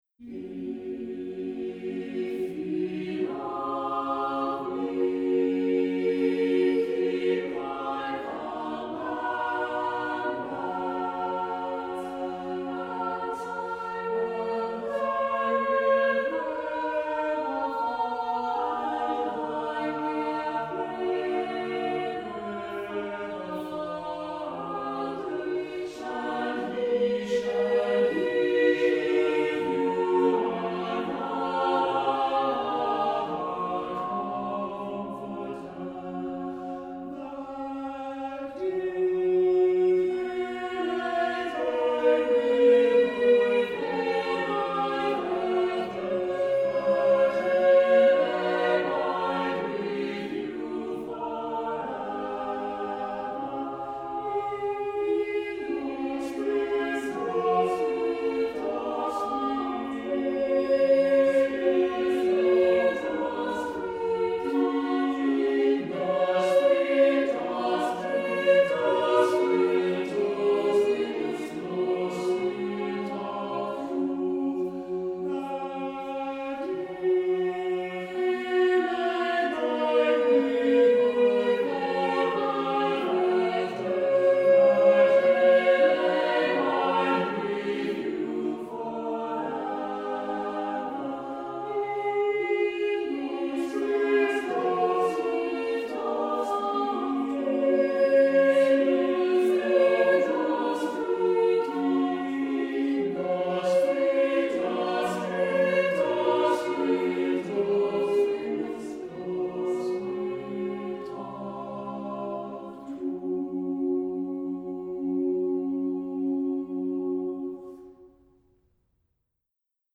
Music Category:      Early Music